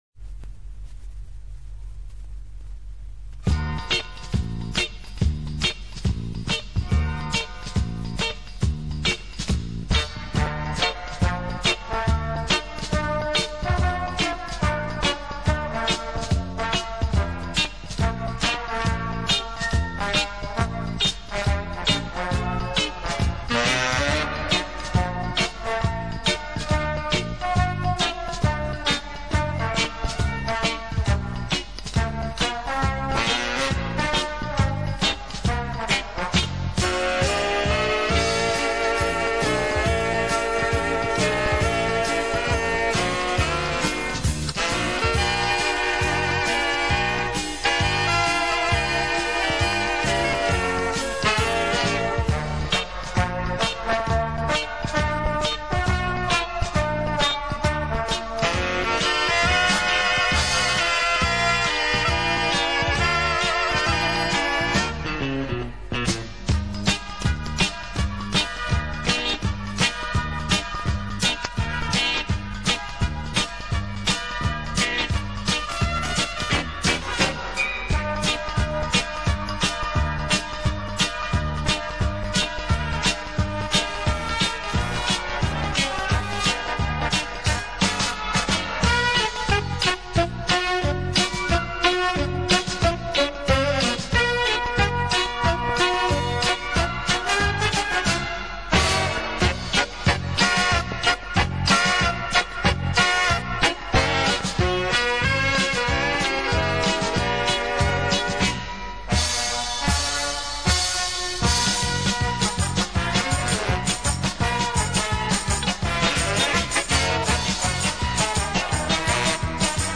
31.Еврейский танец.mp3